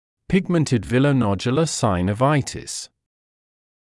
[‘pɪgməntɪd ˌvɪləu’nɔʤjələ ˌsaɪnə’vaɪɪtɪs][‘пигмэнтид ˌвилоу’ноджйэлэ ˌсайнэ’вайтис]пигментный виллонодулярный синовит